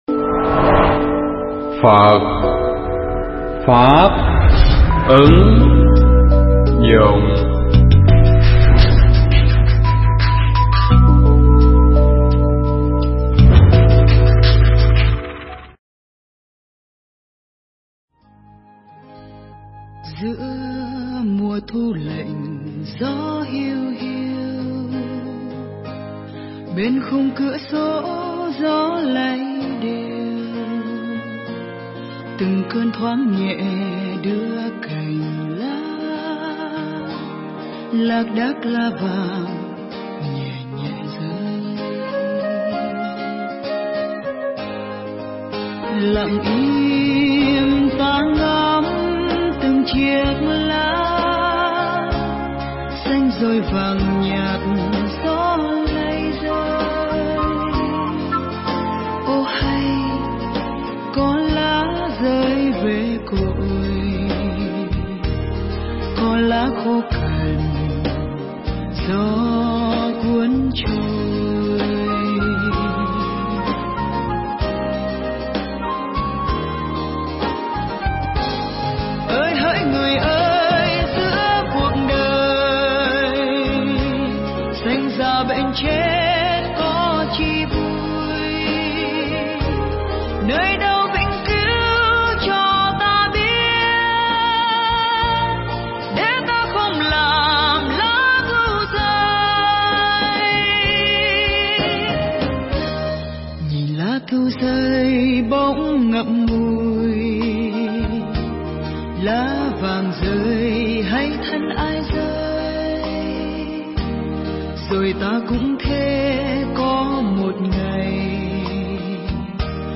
Pháp thoại
tại Chùa Xuân Hòa (123A Nguyễn Văn Cừ, thị xã Long Khánh, tỉnh Đồng Nai)